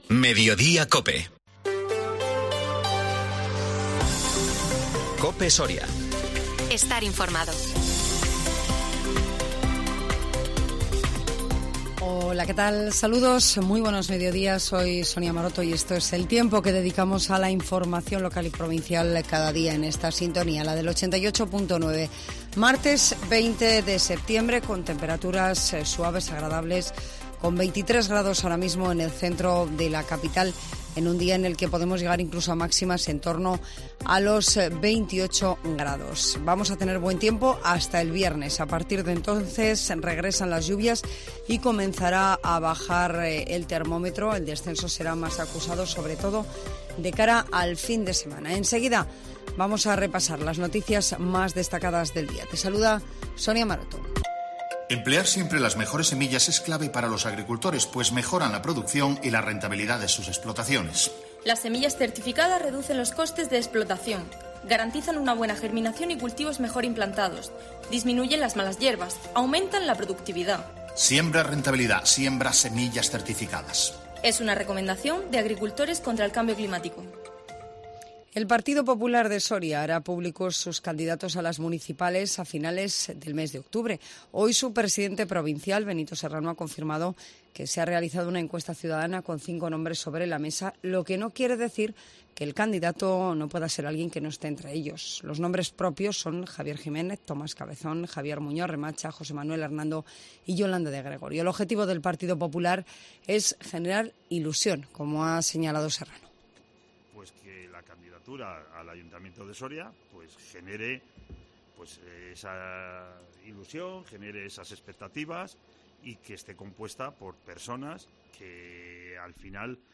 INFORMATIVO MEDIODÍA COPE SORIA 20 SEPTIEMBRE 2022